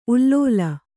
♪ ullōla